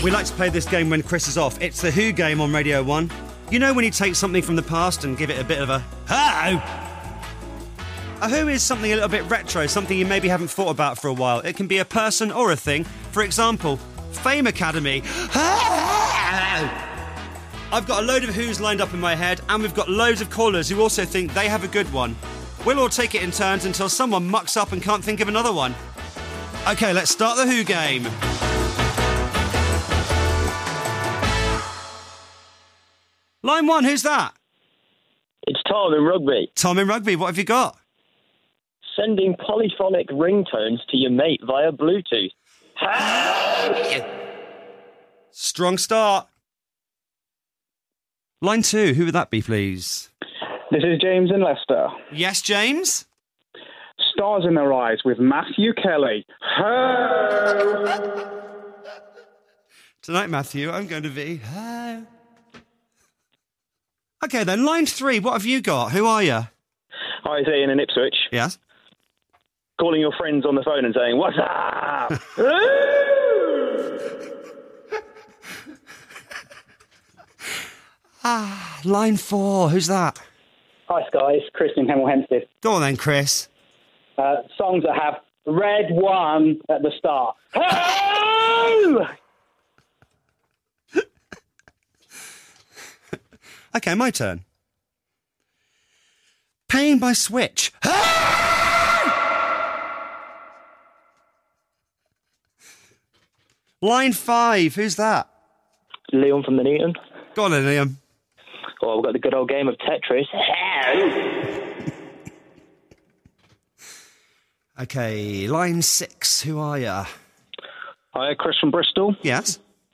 Scott Mills plays The Who Game with his callers on Radio 1.